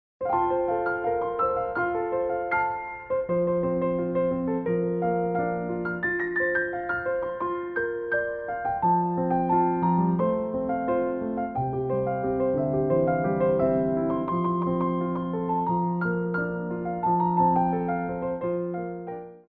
Petit Allegro 2
2/4 (8x8)